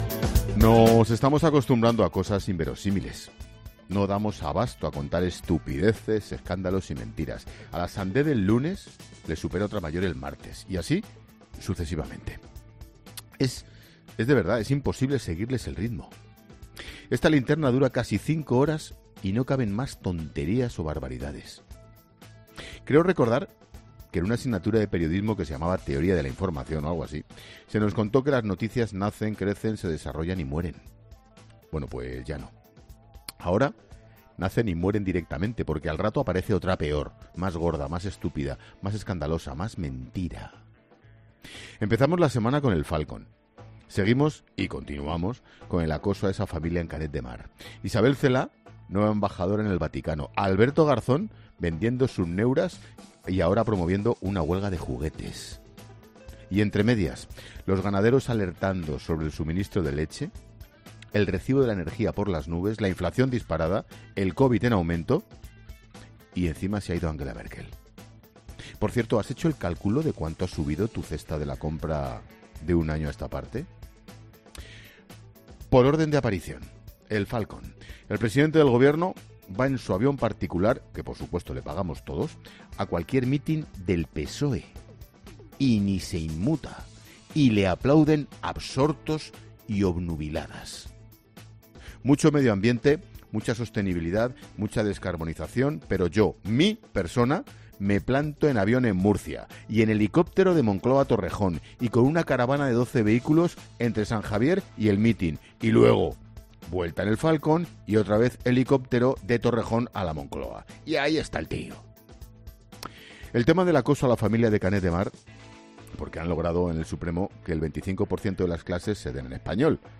Monólogo de Expósito
El director de 'La Linterna', Ángel Expósito, analiza las principales claves informativas de este viernes 10 de diciembre